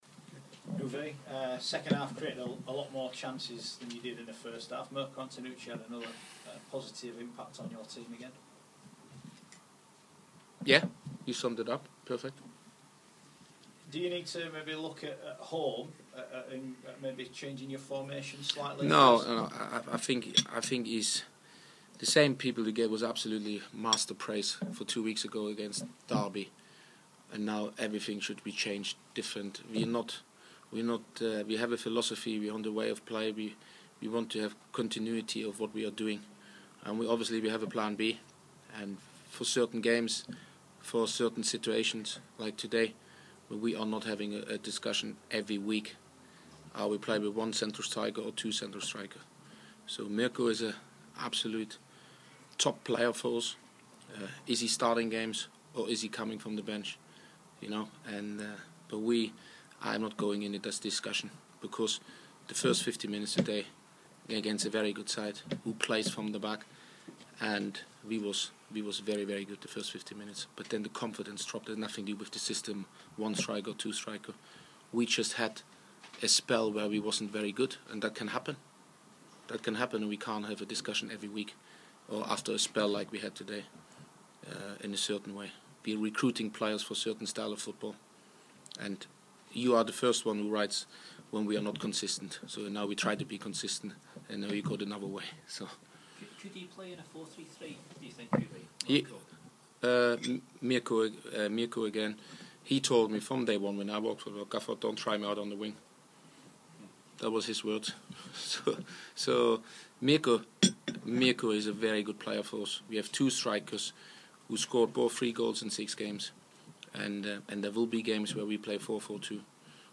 other members of the media spoke to the Leeds United manager after the 1-1 draw with Brentford.